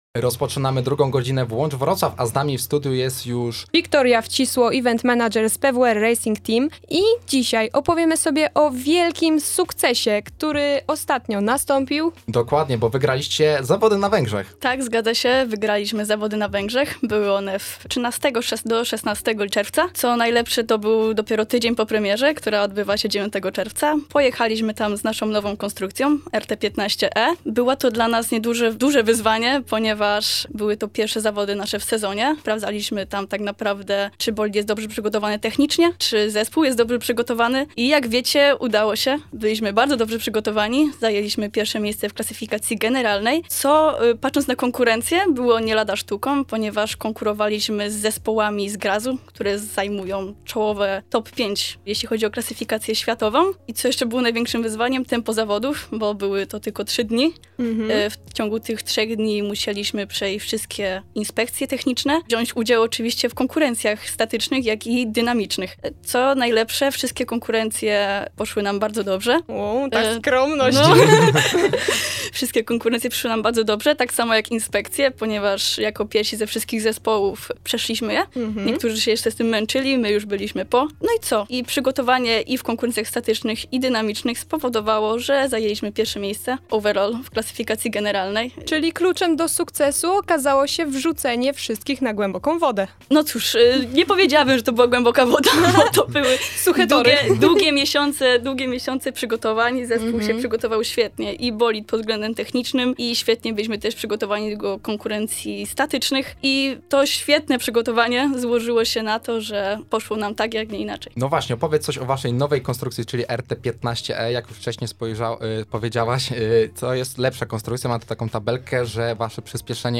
PWr_bolidy_wywiad.mp3